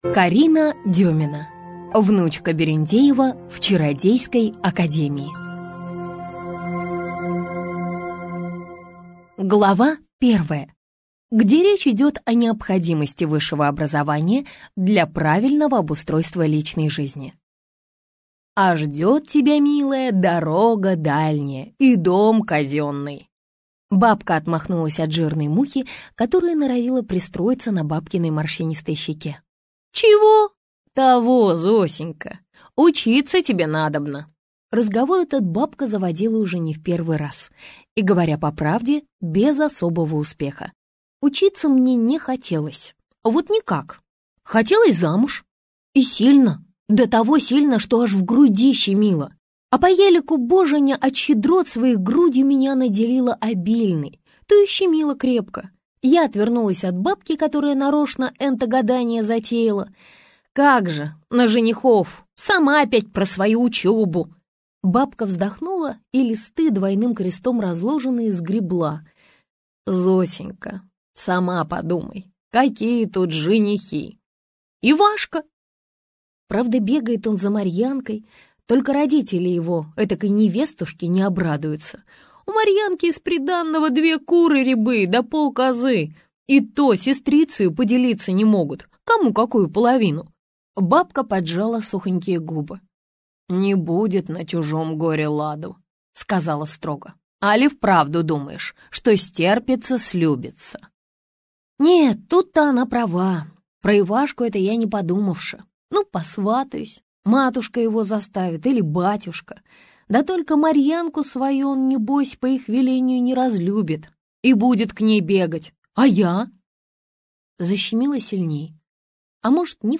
Аудиокнига Внучка берендеева в чародейской академии (часть 1-я) | Библиотека аудиокниг